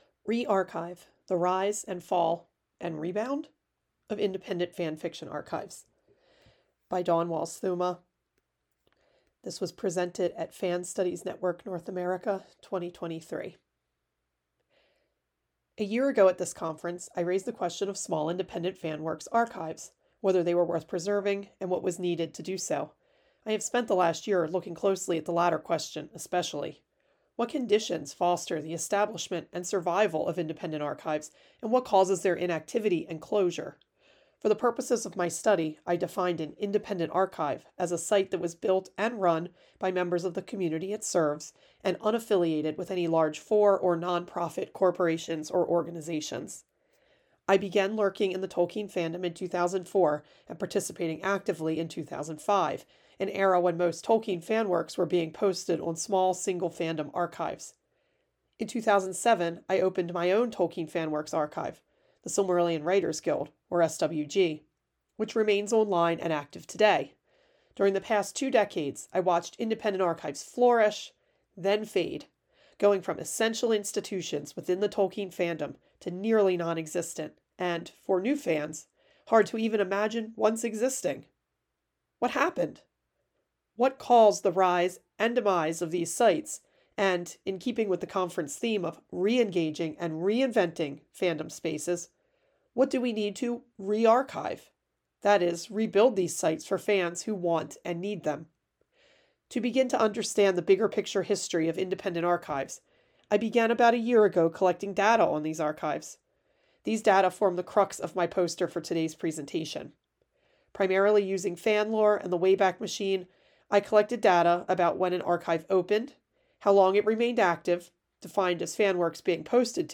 Presentation Audio